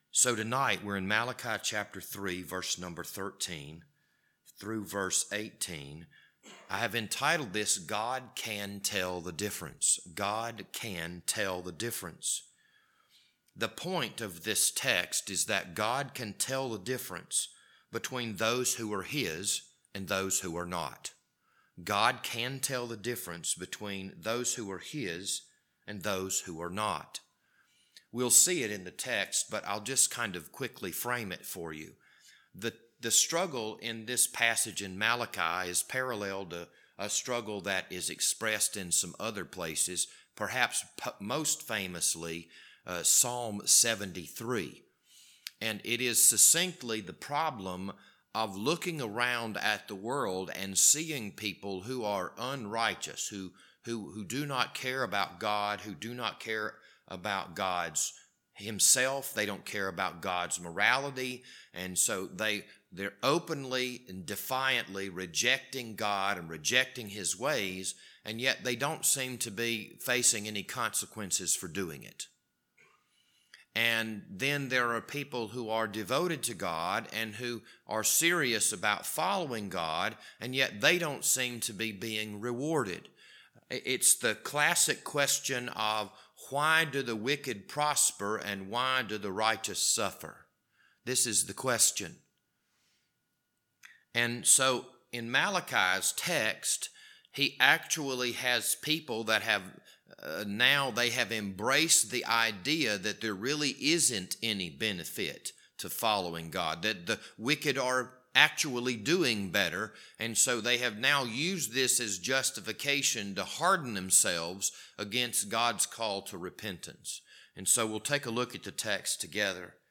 This Wednesday evening Bible study was recorded on September 15th, 2021.